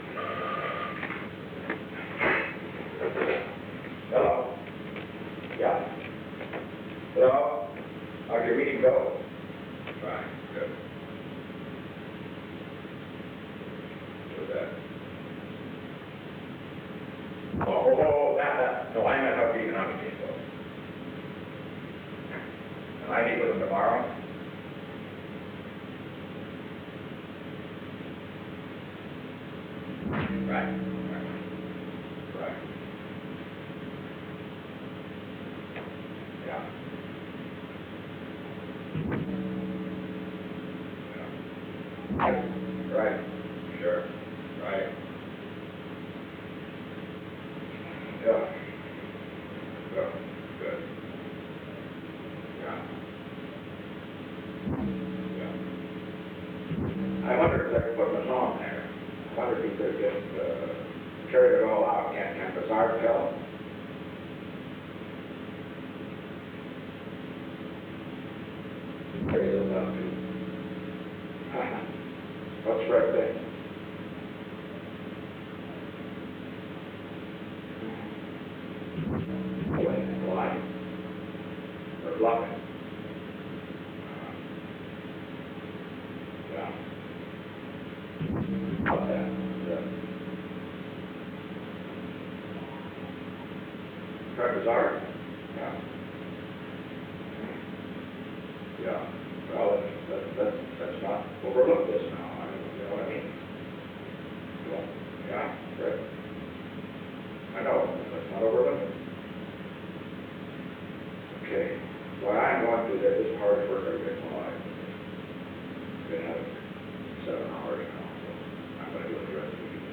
Secret White House Tapes
Location: Executive Office Building
Alexander M. Haig, Jr. talked with the President.